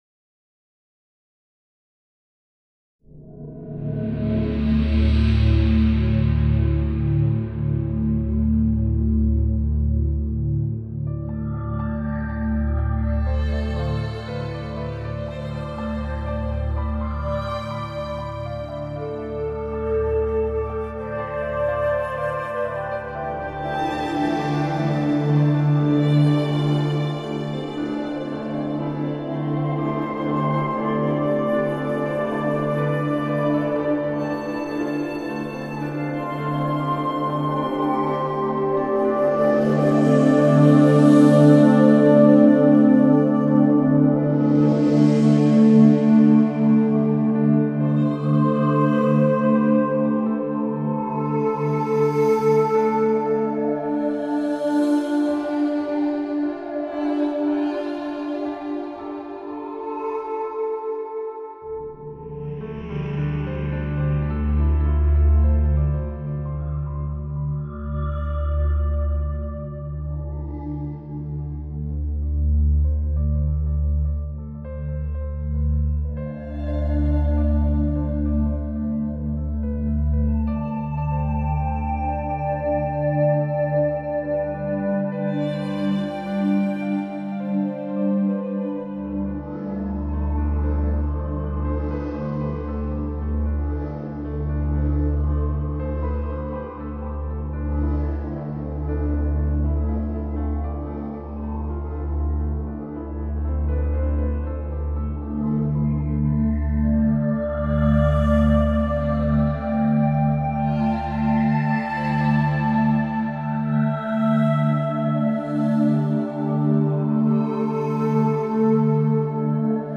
Ein Bespiel für die sehr schöne Hintergrundmusik des Spiels.